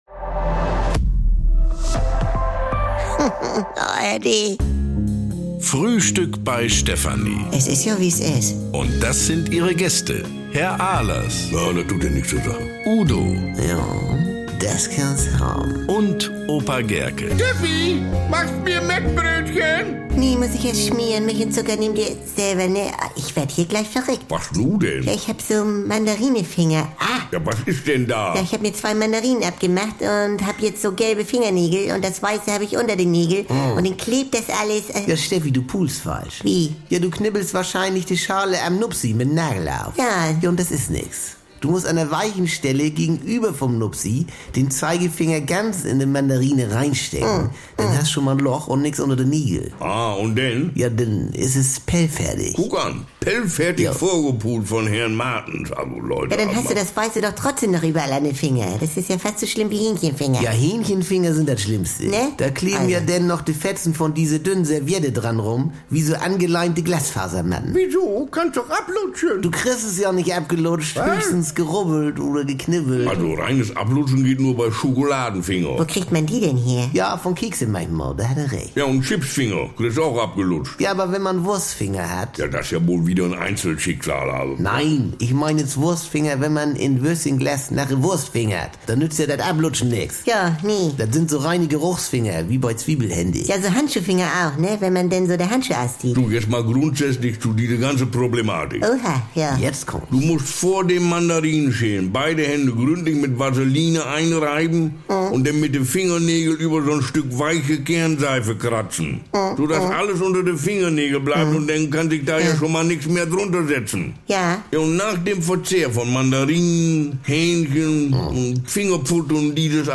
Die besten Folgen der NDR 2 Kult-Comedy